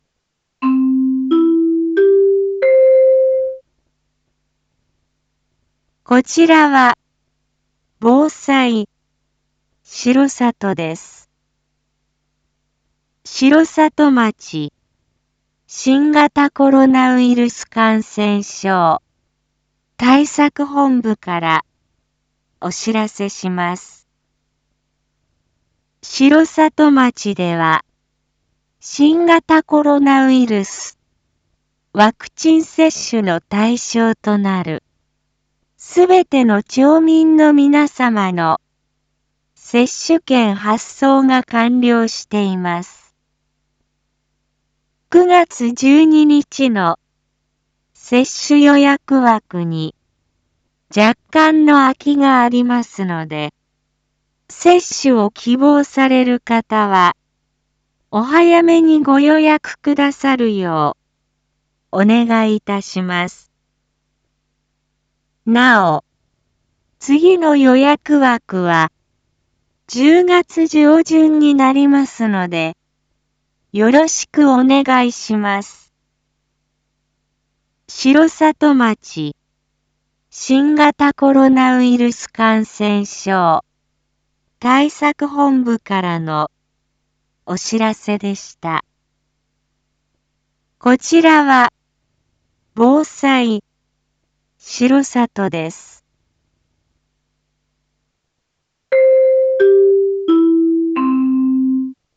一般放送情報
Back Home 一般放送情報 音声放送 再生 一般放送情報 登録日時：2021-08-22 19:01:44 タイトル：R3.8.22放送 新型コロナウイルス感染症ワクチン接種について インフォメーション：こちらは、防災しろさとです。